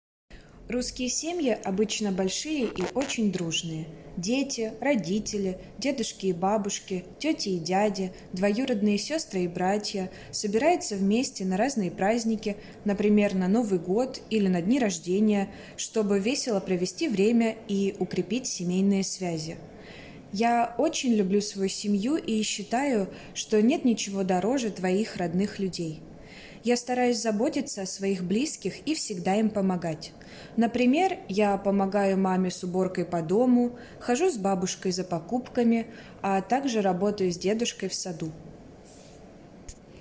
Vous trouverez ici des fichiers mp3 en 14 langues, enregistrés par des locuteurs natifs, libres de droits pour une utilisation pédagogique ou (...)